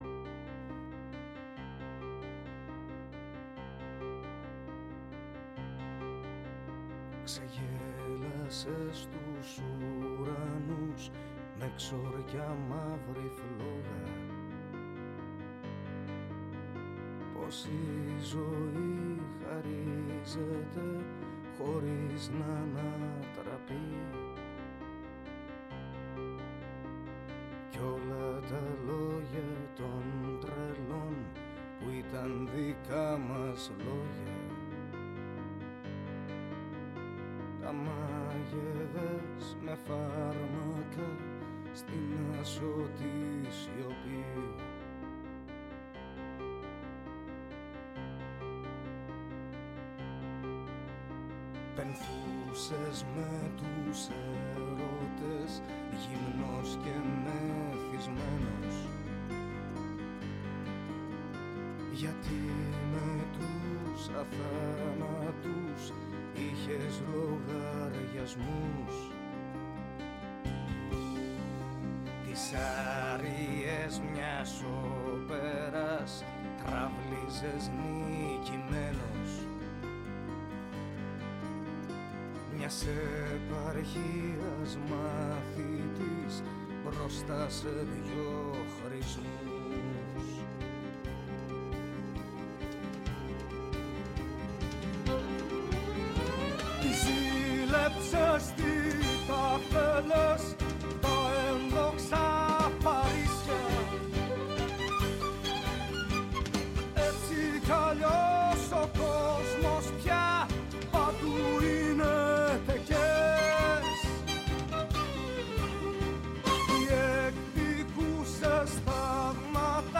Και Ναι μεν η ματιά μας στρέφεται στην εγχώρια επικαιρότητα, Αλλά επειδή ο κόσμος “ο μικρός ο μέγας” -όπως το διατύπωσε ο ποιητής- είναι συχνά ο περίγυρός μας, θέλουμε να μαθαίνουμε και να εντρυφούμε στα νέα του παγκόσμιου χωριού. Έγκριτοι επιστήμονες, καθηγητές και αναλυτές μοιράζονται μαζί μας τις αναλύσεις τους και τις γνώσεις τους.